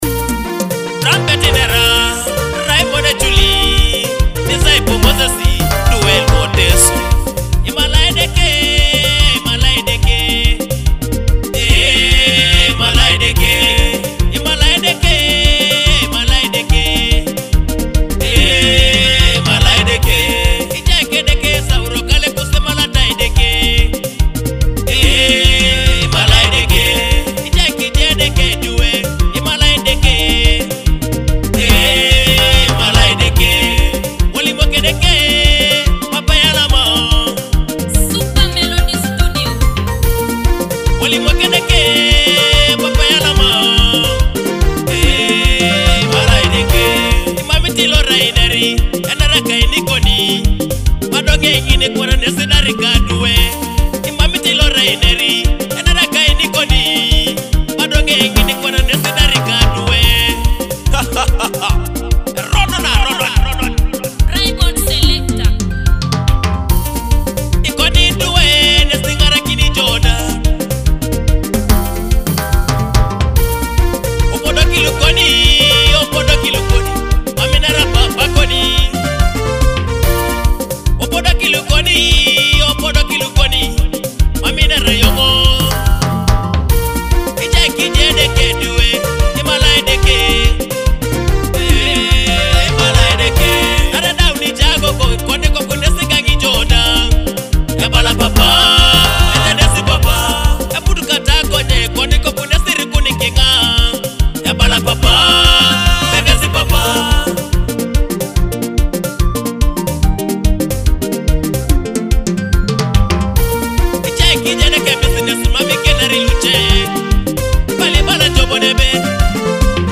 with uplifting Teso rhythms and heartfelt worship